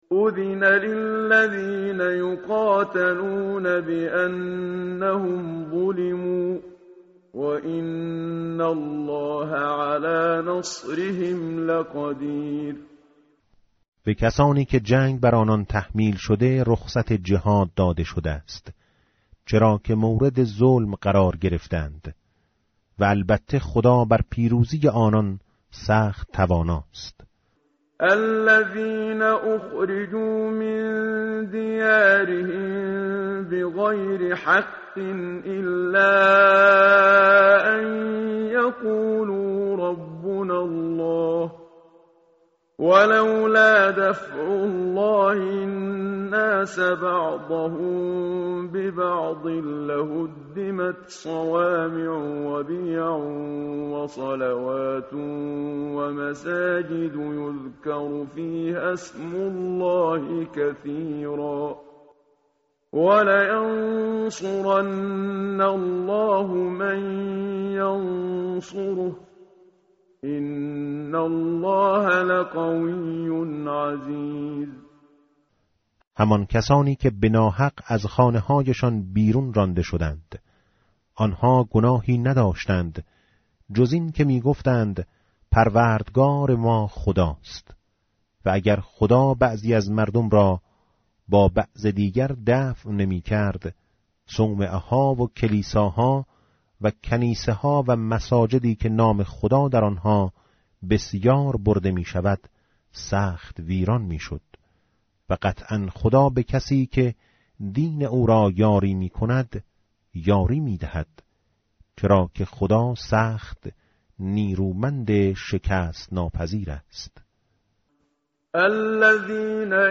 متن قرآن همراه باتلاوت قرآن و ترجمه
tartil_menshavi va tarjome_Page_337.mp3